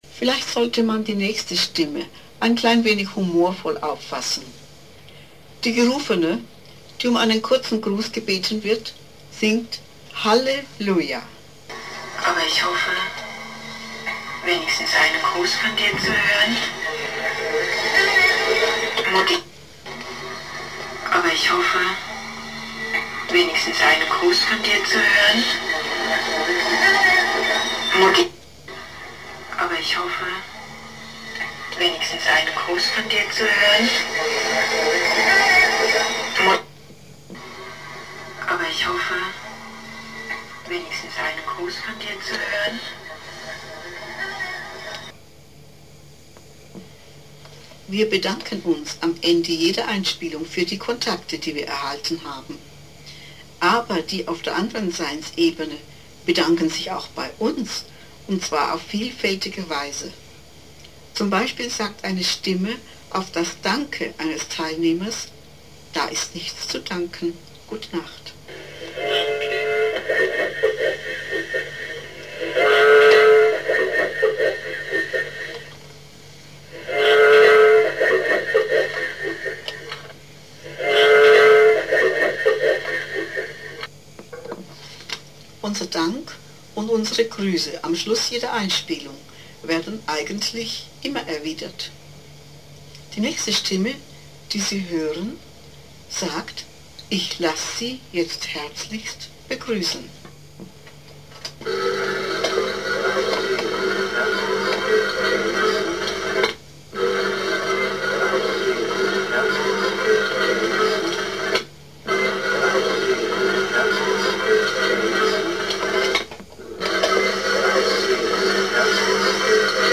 Beispiele paranormaler Tonbandstimmen
Insbesondere f�r Personen, die mit eigenen Einspielversuchen beginnen wollen, ist das Studium dieser, mit verschiedenen Methoden eingespielten Stimmen unerl��lich.